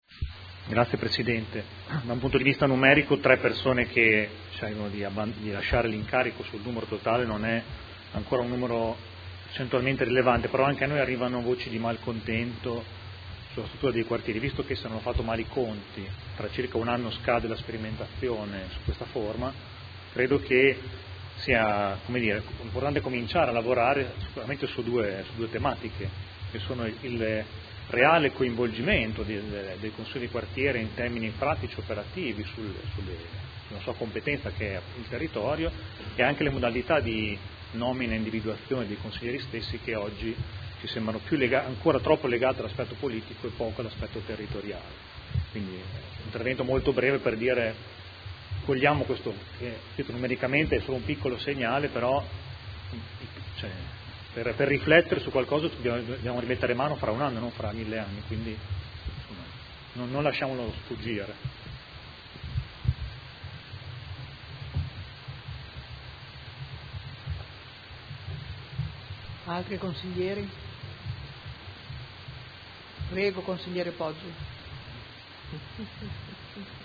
Mario Bussetti — Sito Audio Consiglio Comunale
Proposta di deliberazione: Consiglieri dimissionari dei Quartieri 1 e 3 – nomine nuovi componenti. Dibattito